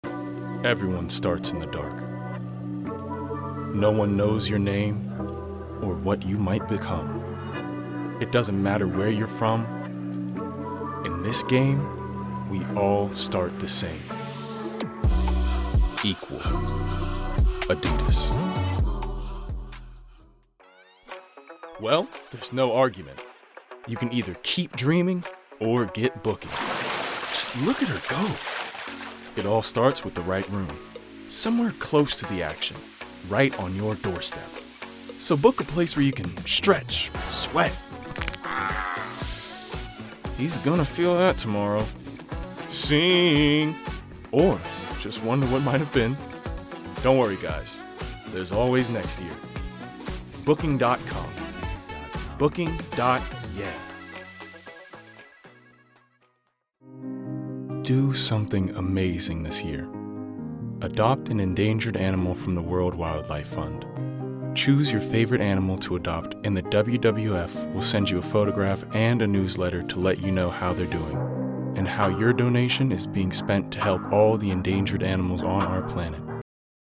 20s-30s. US. A deep, resonant voice with great range. Calm and assured to boisterous and brazen.
Commercials